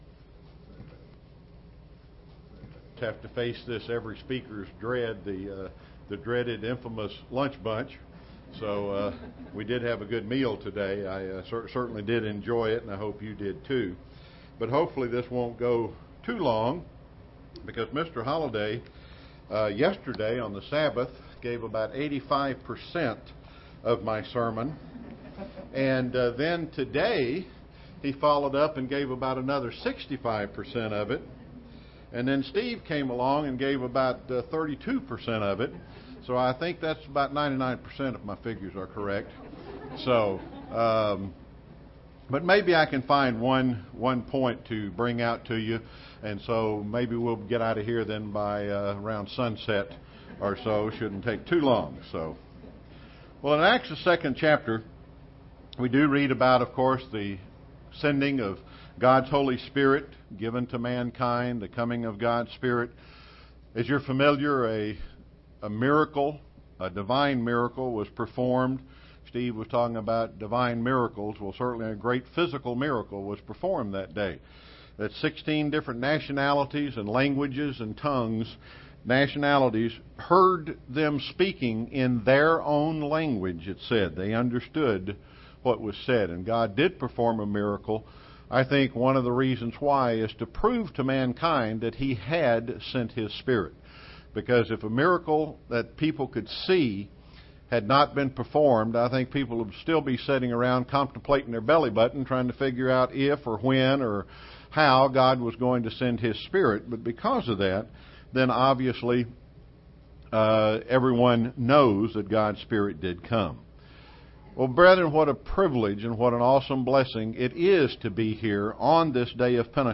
Given in Chattanooga, TN
UCG Sermon Studying the bible?